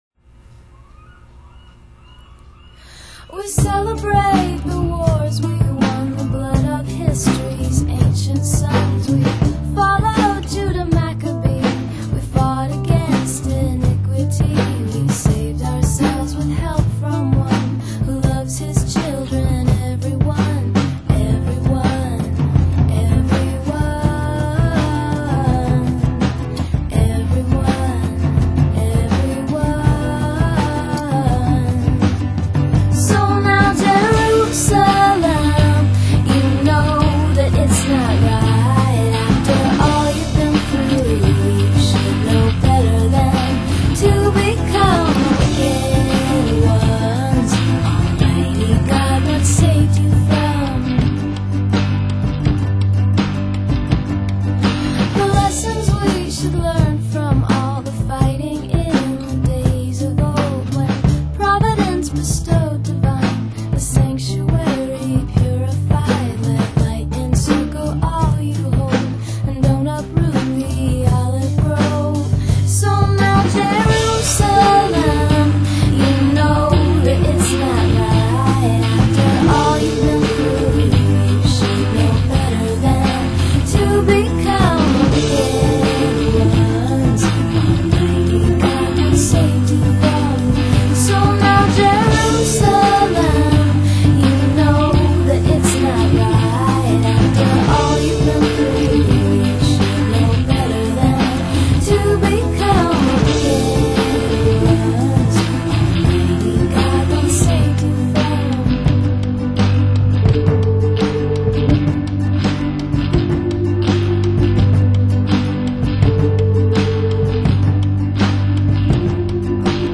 STYLE: Indie-Pop, Indie-Rock YEAR